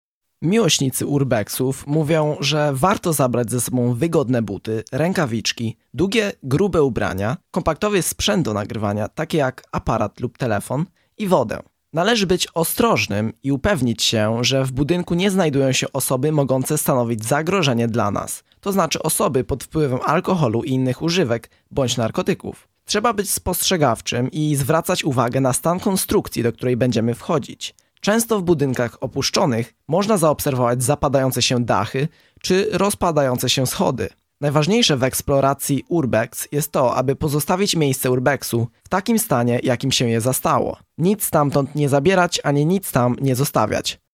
O tym, co trzeba wiedzieć przed pierwszą wycieczką typu urbex, mówi nam doświadczony miłośnik tej formy aktywności.